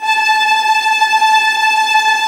VIOLINT BN-R.wav